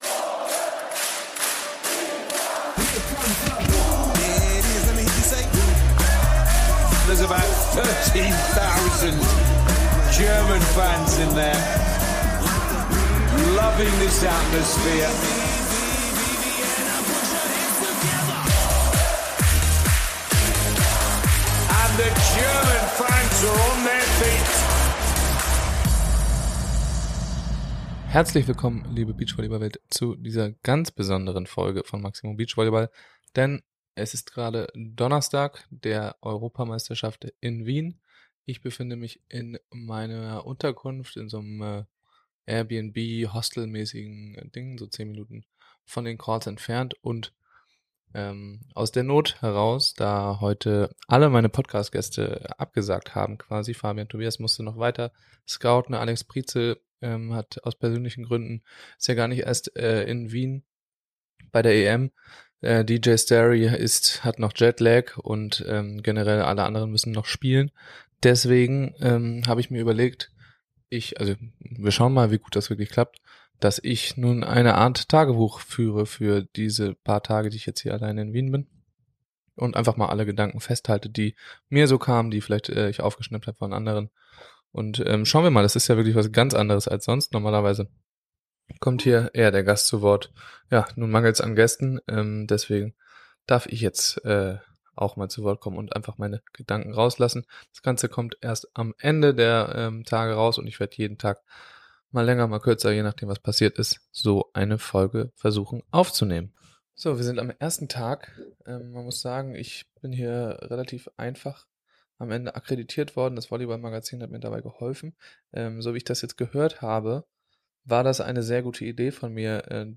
Ich habe durch den ganzen Turnierverlauf meine Gedanken eingesprochen und diese könnt ihr euch nun im nachhinein anhören.